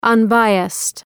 Προφορά
{ʌn’baıəst}